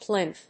/plínθ(米国英語)/